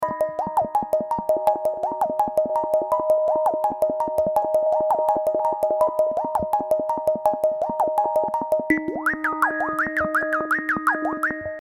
Tag: 83 bpm Rap Loops Synth Loops 1.95 MB wav Key : Unknown